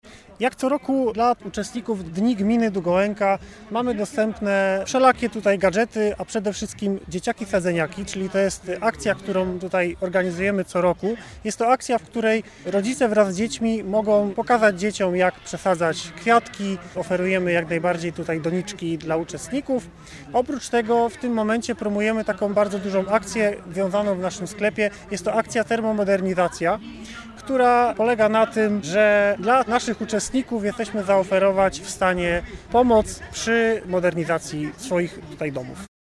W Parku w Szczodrem odbył się piknik, dopisały frekwencja oraz pogoda.